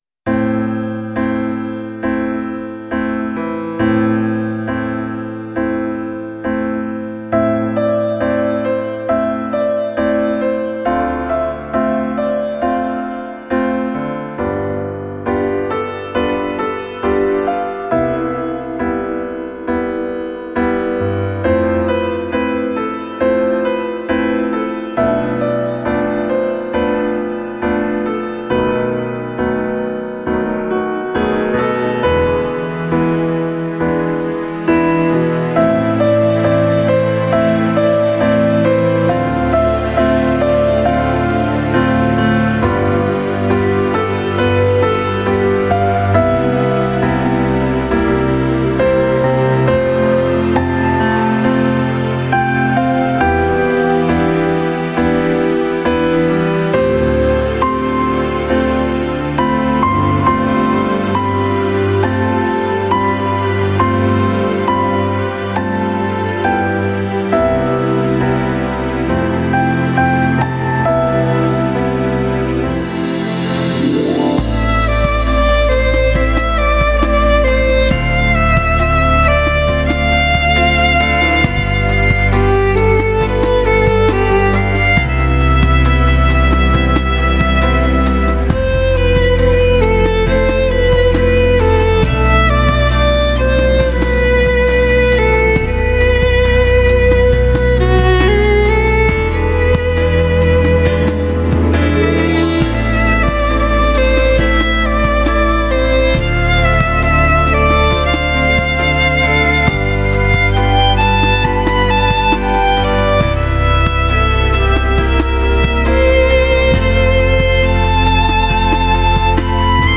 Жанр- Классическая музыка
Инструмент- скрипка мастера Антонио Страдивари 1698 год